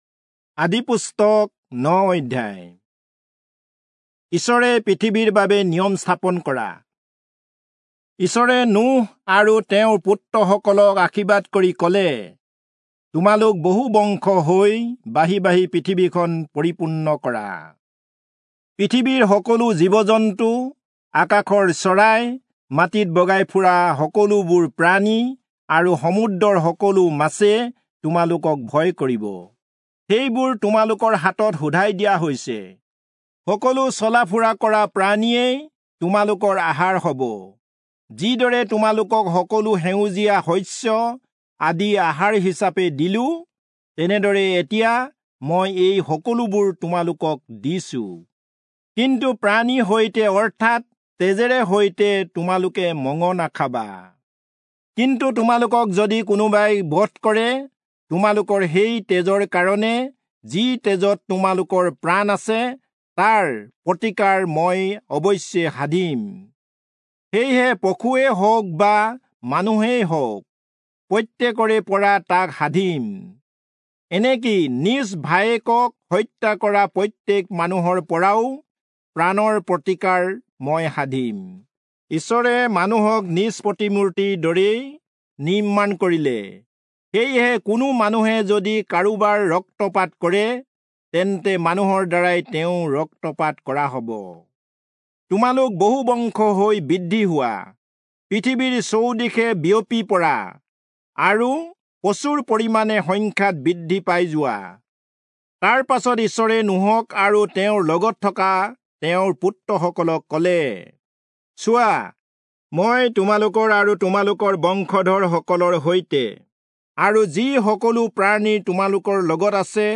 Assamese Audio Bible - Genesis 6 in Pav bible version